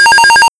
New cricket pattern
cricket.wav